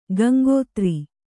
♪ gaŋgōtri